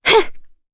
Veronica-Vox_Attack1_jp.wav